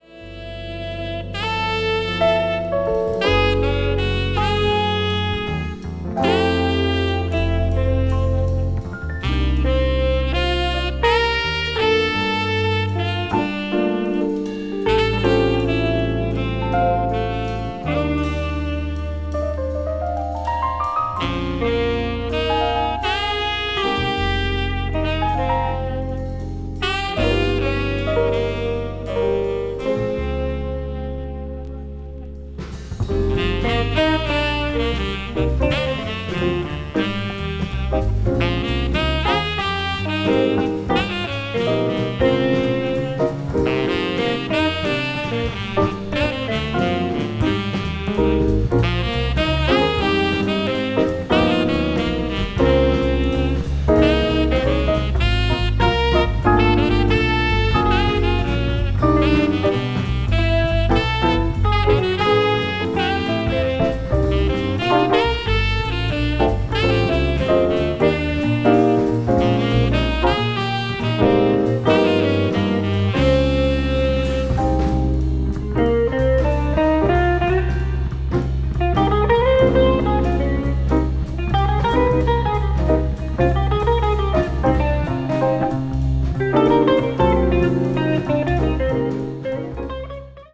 contemporary jazz